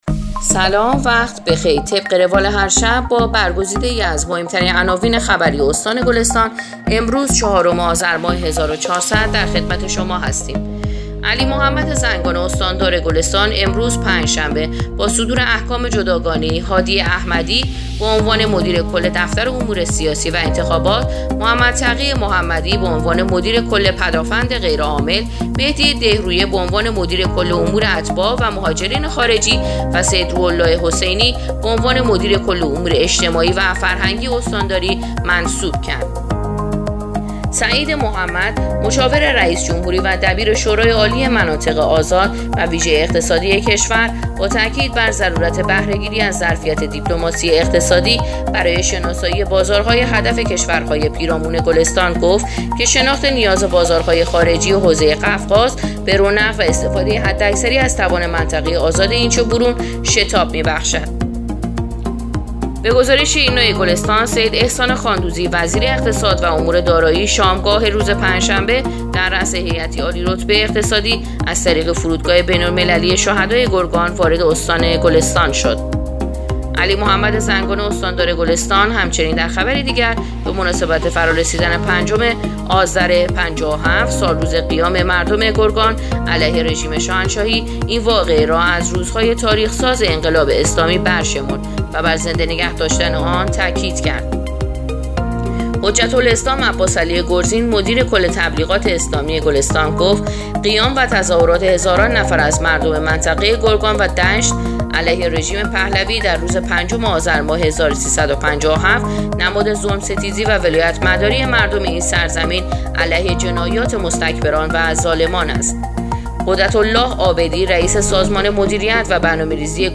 پادکست/اخبار شامگاهی چهارم آذر ایرنا گلستان